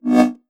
countdown-warn.wav